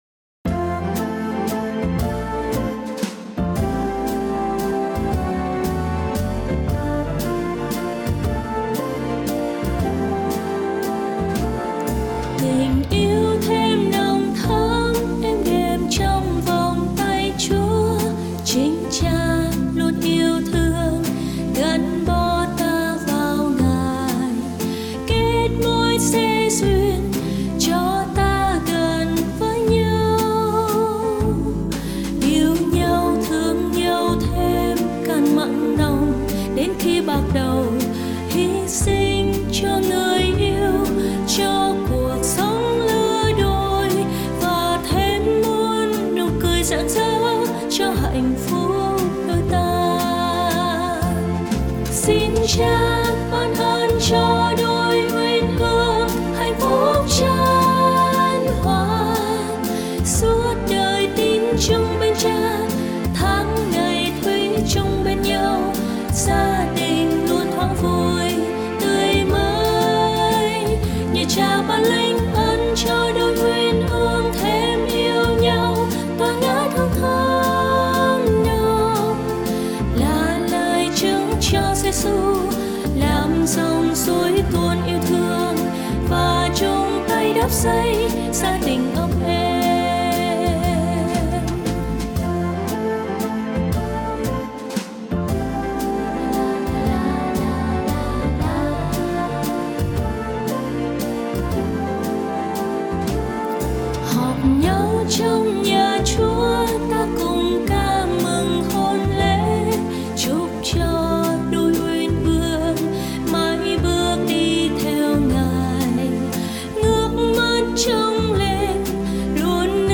Bài hát mừng hôn lễ